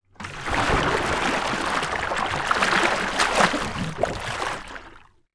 Water_42.wav